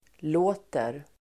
Uttal: [l'å:ter]